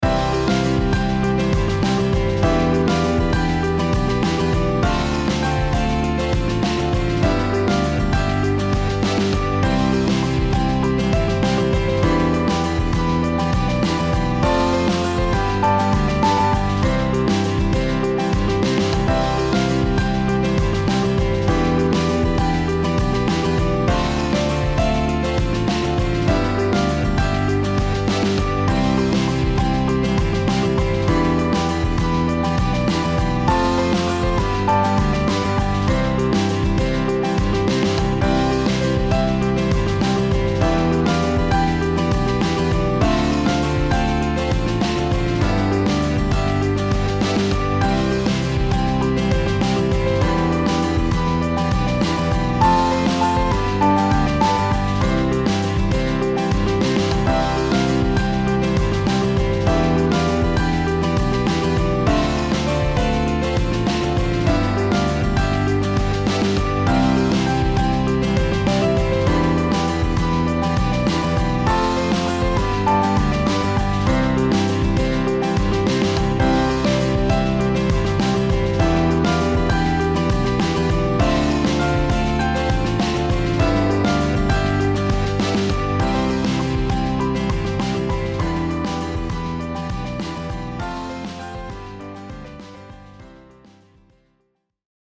カントリーショート明るい穏やか